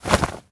foley_object_throw_move_05.wav